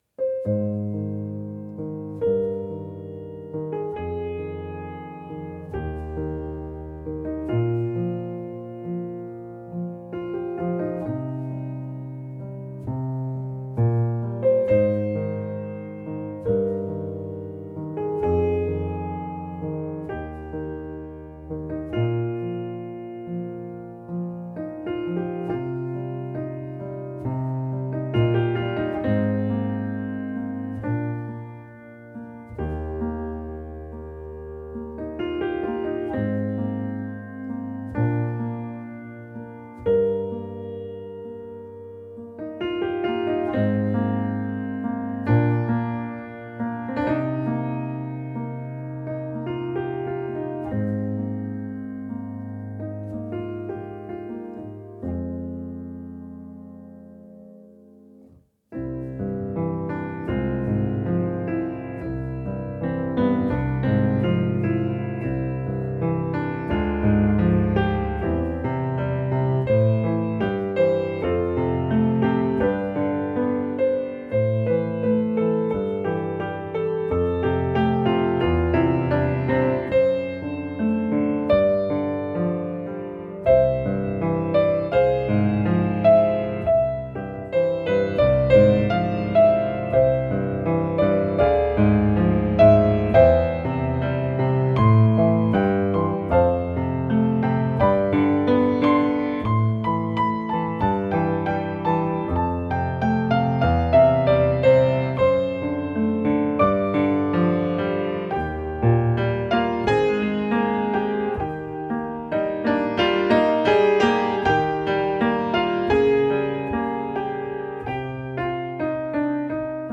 Genre: Crossover
at the Piano